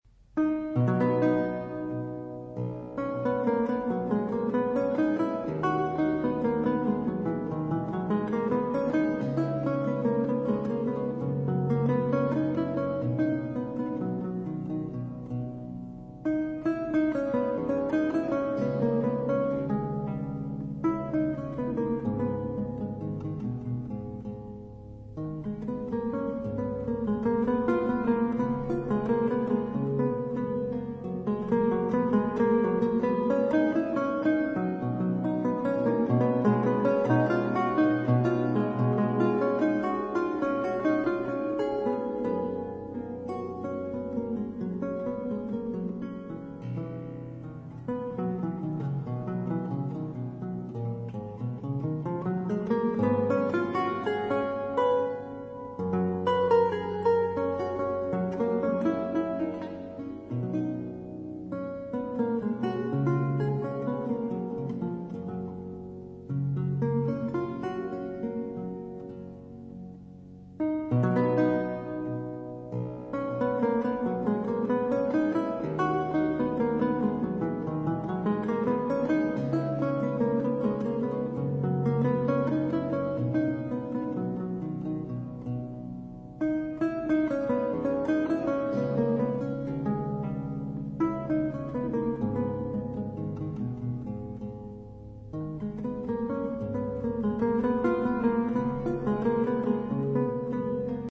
平易好聽，可以細聽，也可以背景，